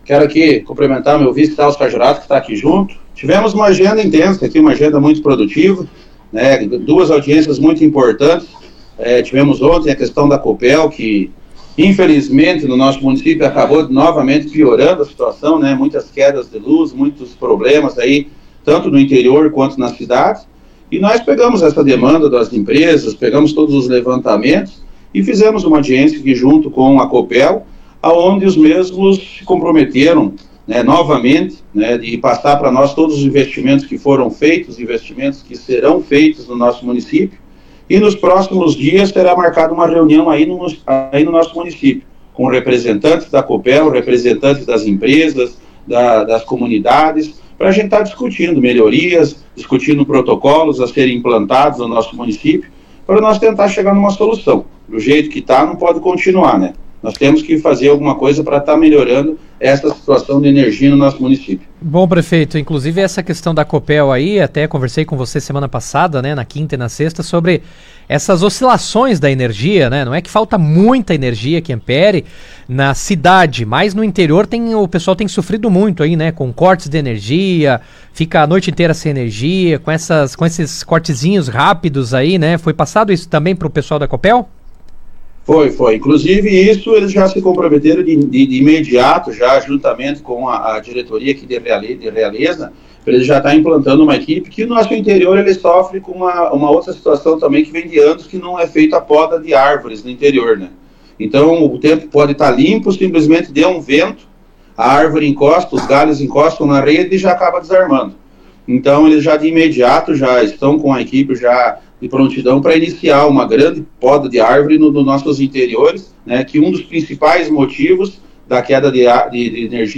O prefeito Douglas Potrich, acompanhado pelo vice Celso Saggiorato, está em Curitiba e, em audiência com representantes da empresa de energia, repassou as reclamações. No Jornal RA 2ª Edição desta quarta-feira, 26, o prefeito falou ao vivo de Curitiba.